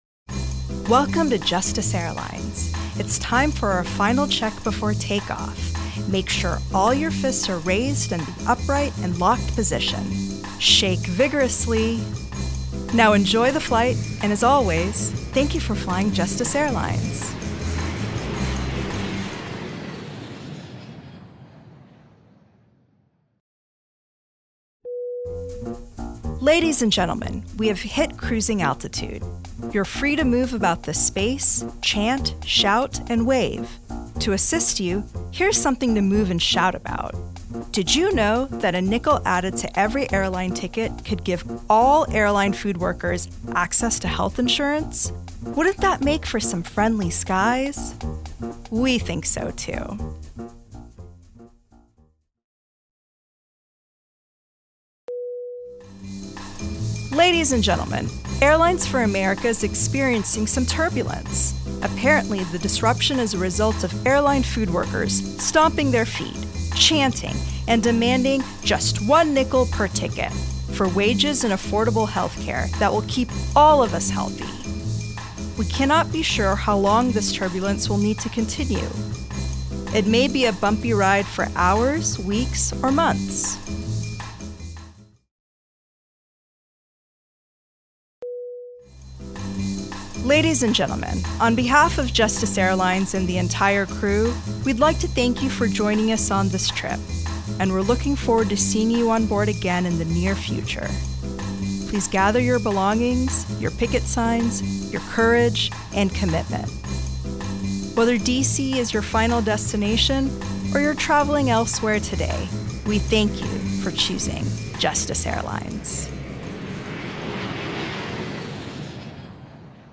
On 5 days notice, to bring some levity to an action today in support of airline food service workers, we came up with the idea of making a series of flight attendant voiceover bits that reinforce messages of the campaign while clearly aligning with the industry in question for the workers and supporters present.
Running hard with the airline metaphors for cleverness, these audio bit were played at specific points in the rally program in between speakers, as a narrative arc from Take Off > Cruising > Turbulence > Landing.
UNH-Full-Flight-Voiceover.mp3